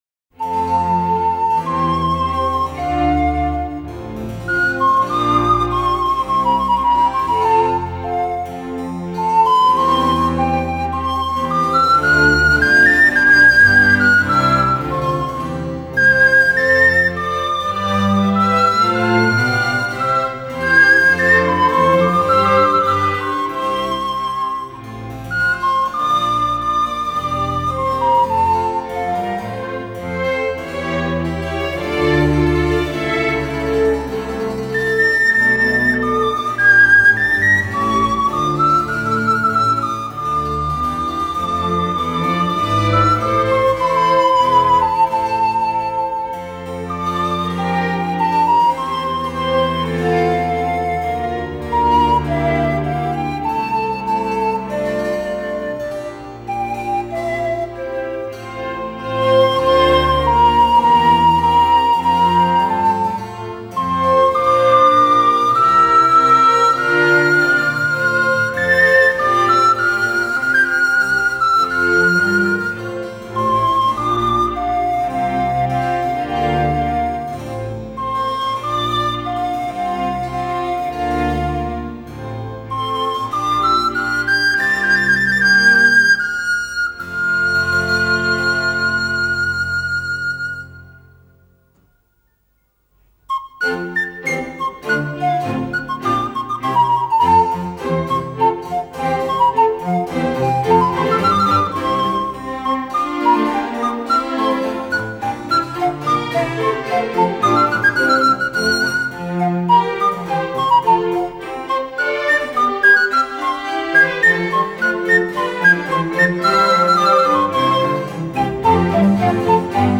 Panflöte mit Kammerorchester:
Konzert für Panflöte und Kammerorchester